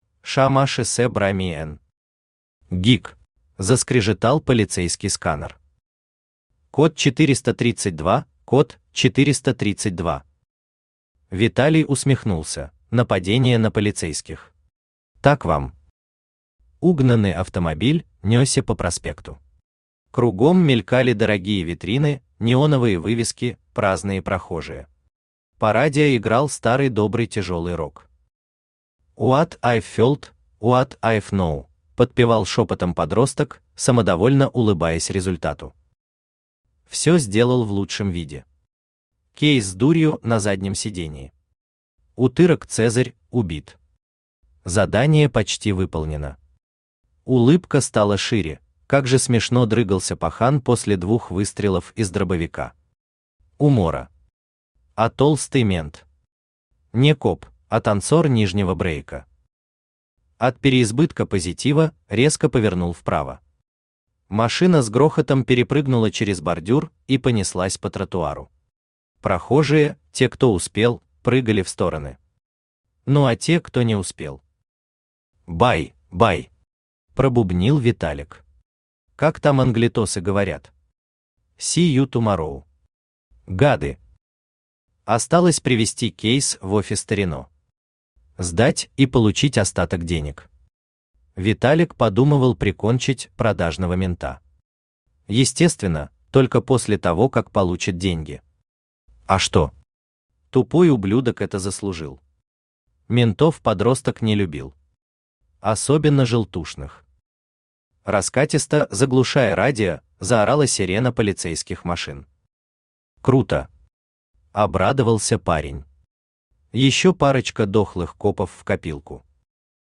Аудиокнига Гик | Библиотека аудиокниг
Aудиокнига Гик Автор ШаМаШ БраМиН Читает аудиокнигу Авточтец ЛитРес.